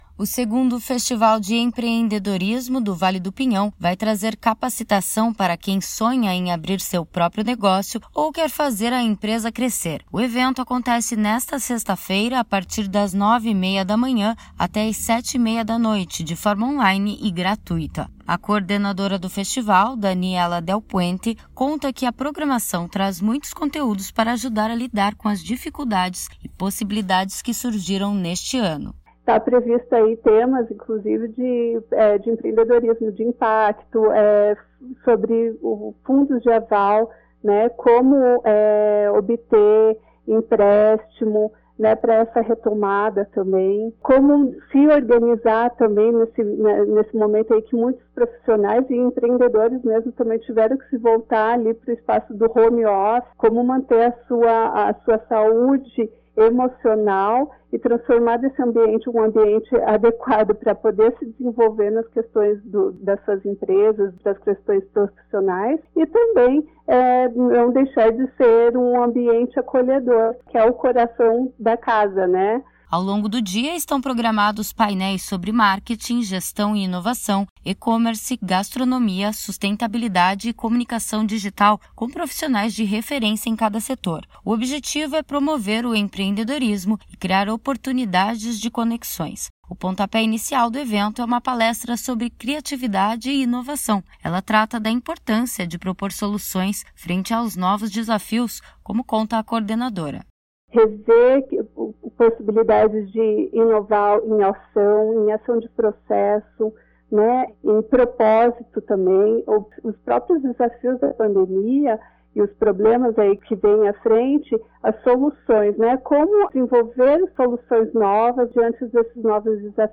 Festival de Empreendedorismo online ajuda a lidar com desafios da pandemia e de crescimento do negócio. Os detalhes na reportagem.